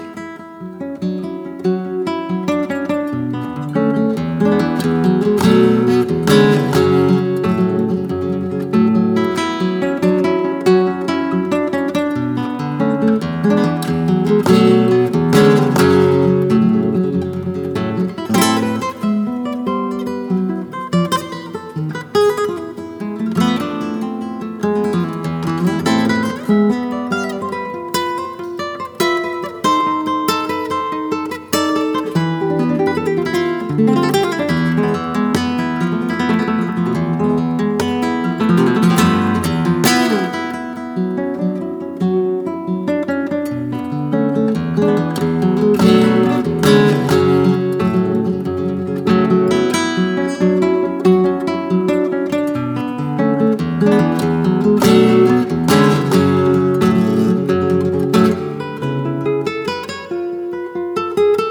Zurück zu: Flamenco